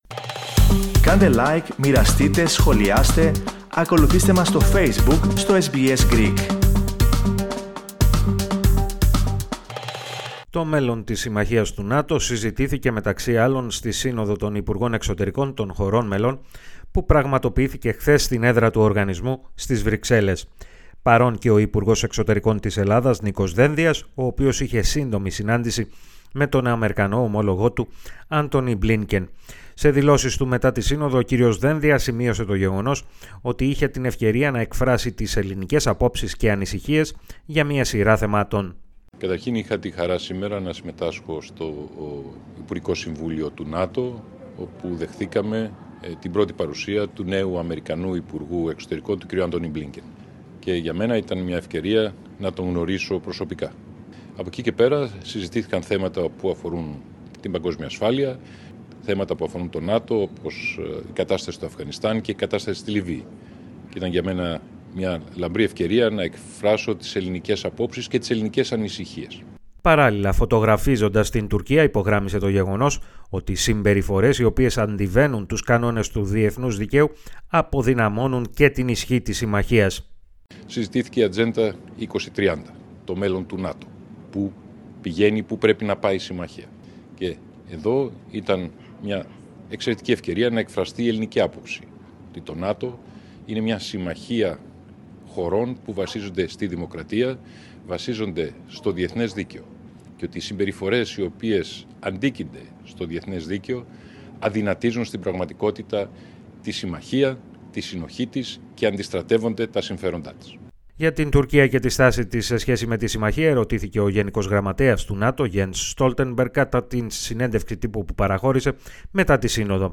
Περισσότερα ακούστε στην αναφορά